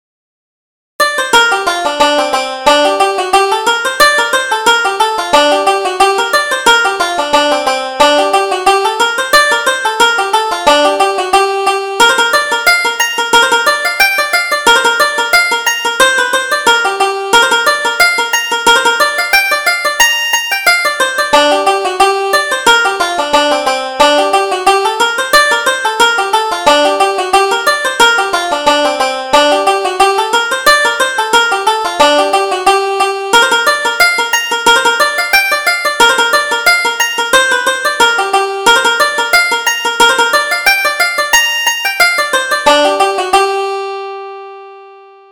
Reel: Thompson's Reel